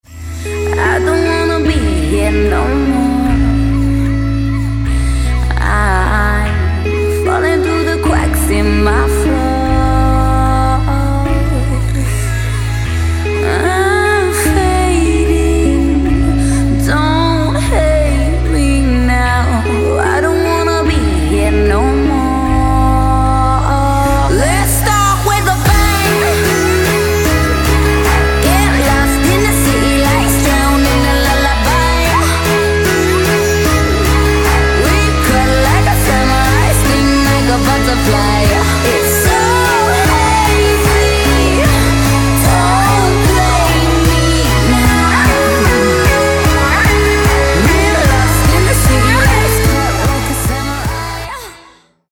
• Качество: 160, Stereo
поп
женский вокал
dance
vocal